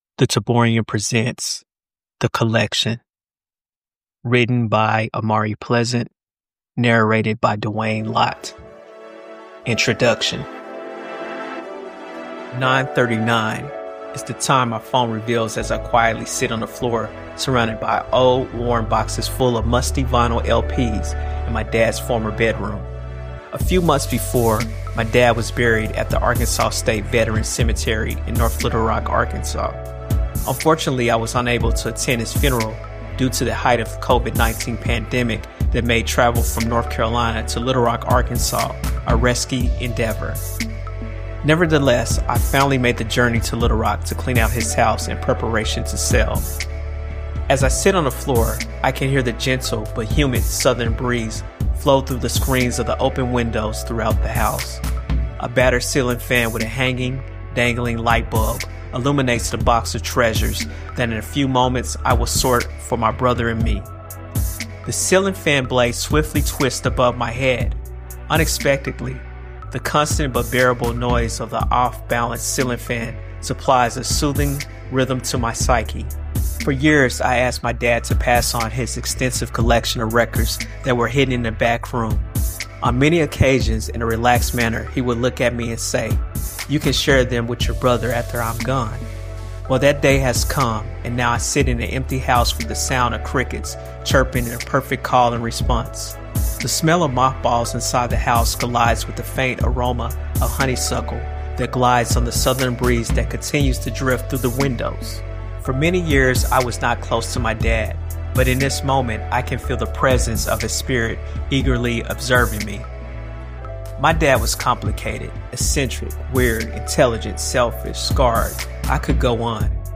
The Collection is an audio essay exploring a son's journey through his late father's records—connecting through music and reflecting on its meaning.
The-Collection-Introduction-with-background-finished.mp3